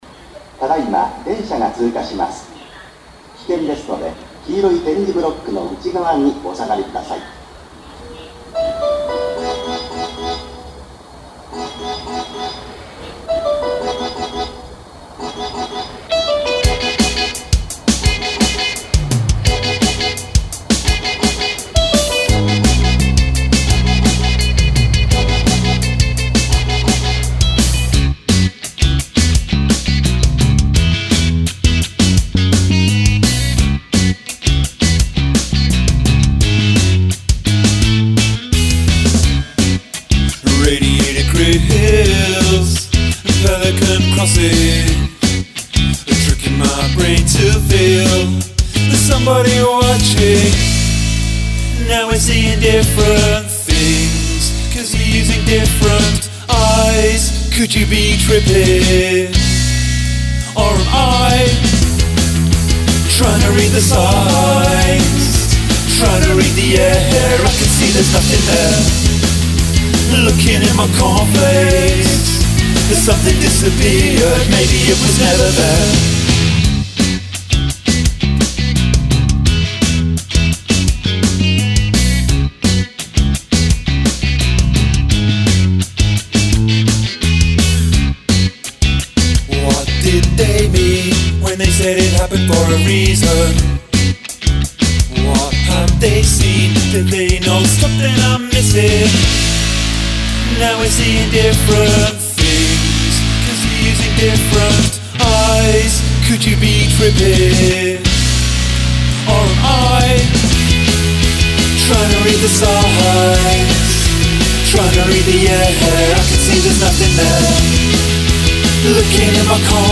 Use of field recording
The vocal doesn't sound off-key to me.
Book ending the field recordings seems to be popular.